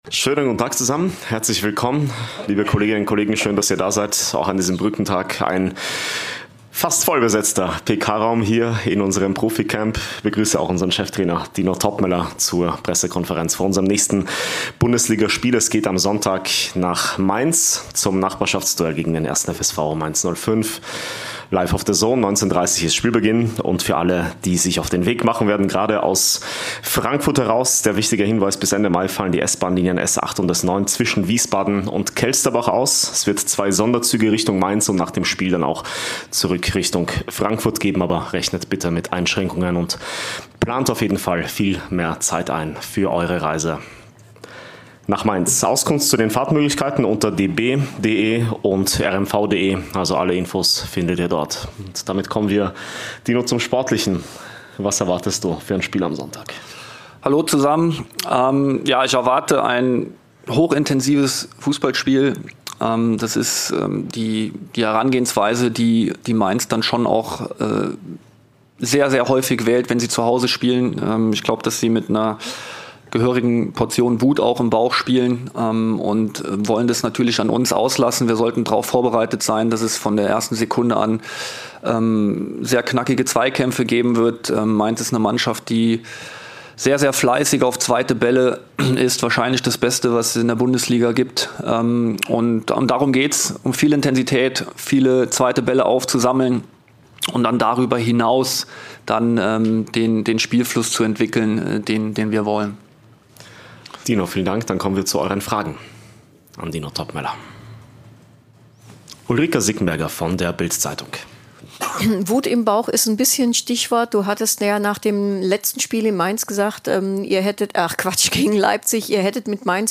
Die Pressekonferenz mit Cheftrainer Dino Toppmöller vor dem Bundesliga-Auswärtsspiel beim 1. FSV Mainz 05 .